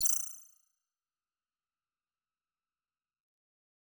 Base game sfx done
Futurisitc UI Sound 13.wav